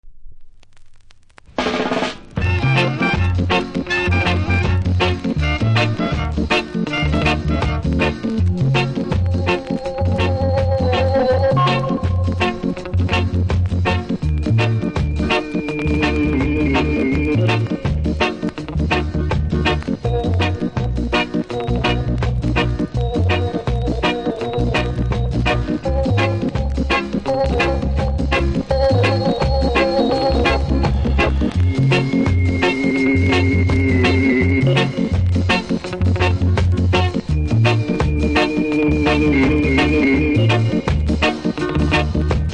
キズ多めノイズもそこそこありますがプレイは問題無いレベル。